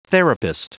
Prononciation du mot therapist en anglais (fichier audio)